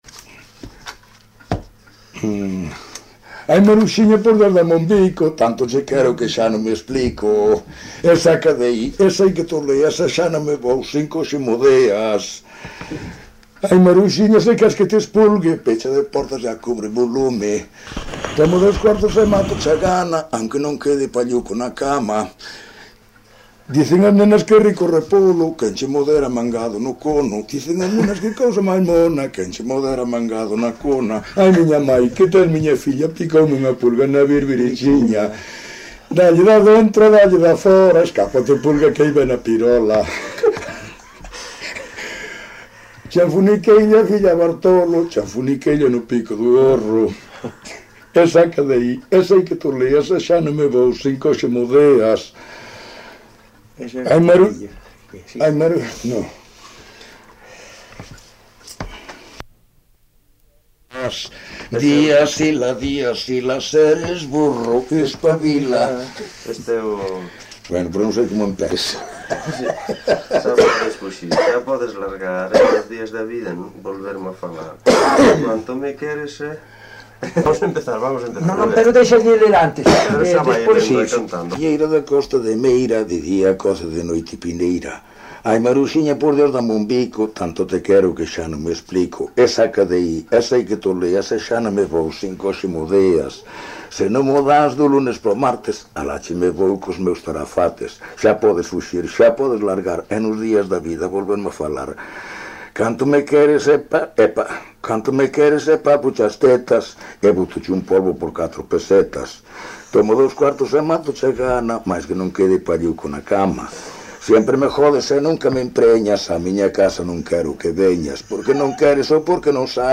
Palabras chave: muiñeira Maruxiña
Áreas de coñecemento: LITERATURA E DITOS POPULARES > Cantos narrativos
Soporte orixinal: Casete
Xénero: Muiñeira nova
Instrumentación: Voz
Instrumentos: Voz masculina